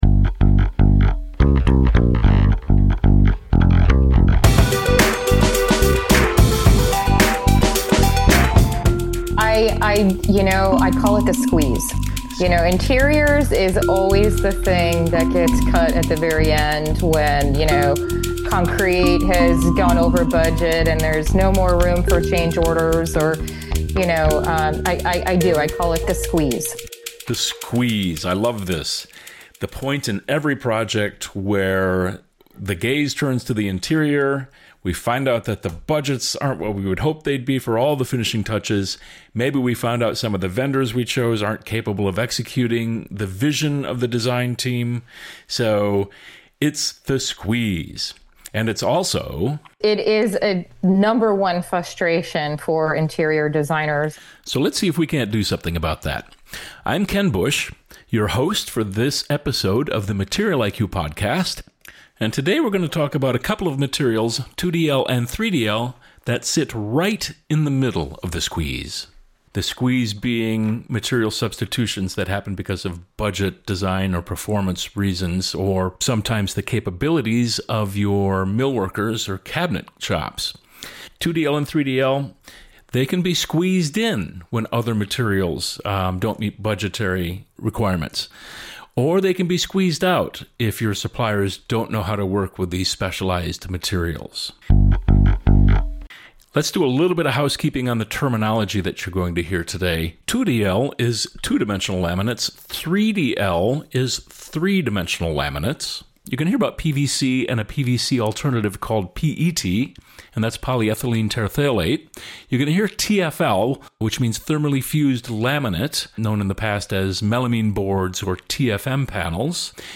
From project bidding challenges and specification “doom loops” to the importance of early collaboration between designers, millworkers, and suppliers, this conversation offers practical insights into avoiding costly material substitutions and achieving cohesive, high-performing interiors.